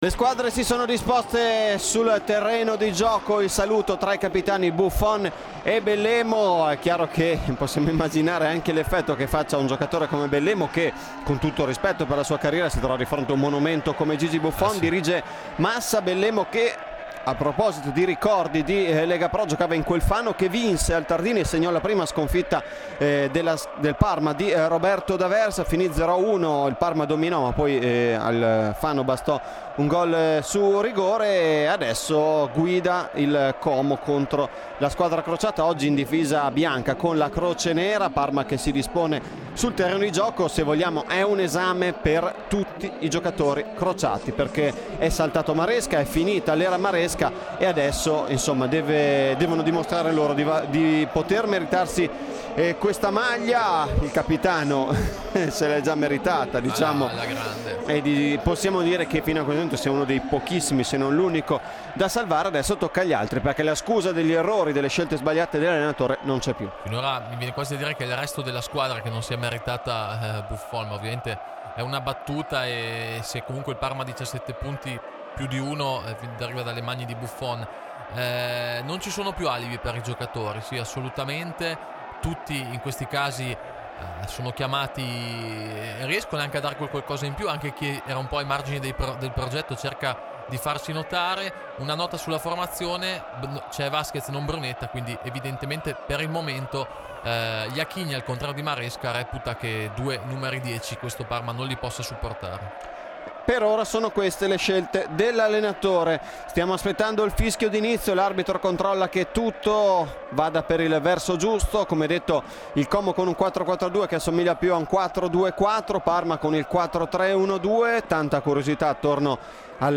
Radiocronache Parma Calcio Como - Parma 28 dicembre 2021 - 1° tempo Nov 28 2021 | 00:50:49 1x 00:00 / 00:50:49 Subscribe Share RSS Feed Share Link Embed